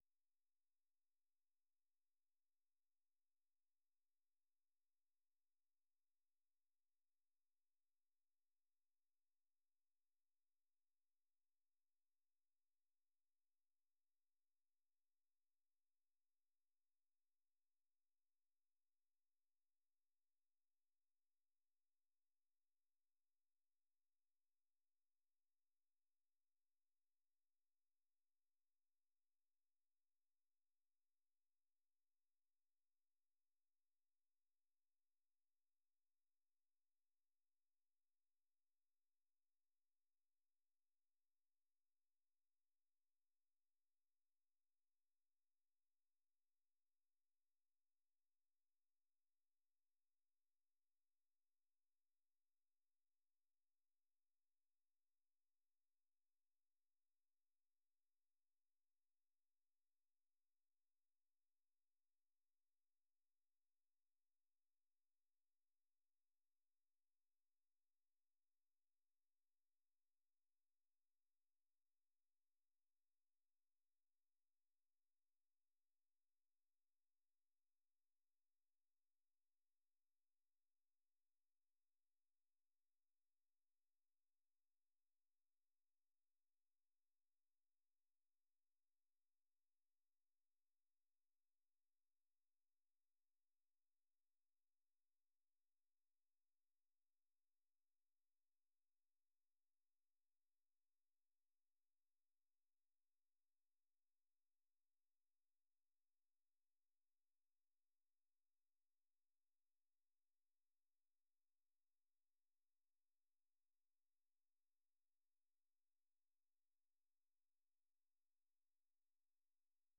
VOA 한국어 방송의 월요일 새벽 방송입니다.